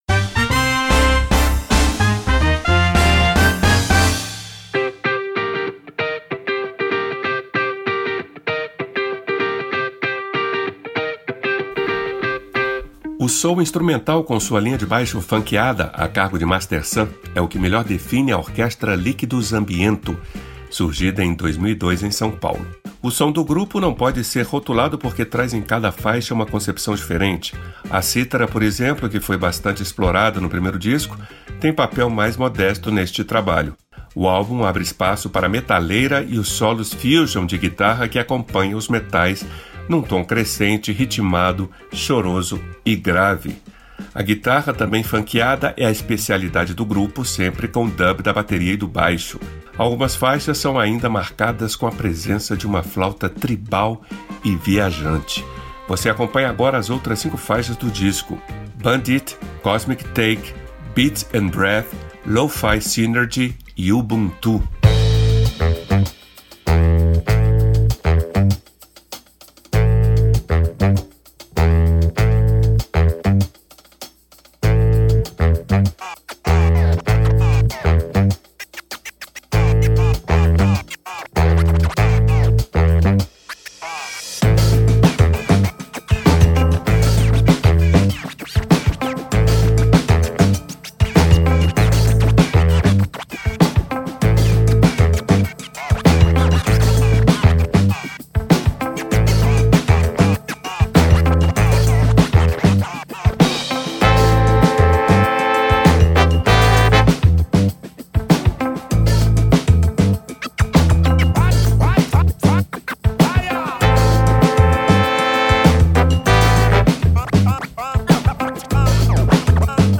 pgm-esquina-do-jazz-liquidus-ambiento-bl-02.mp3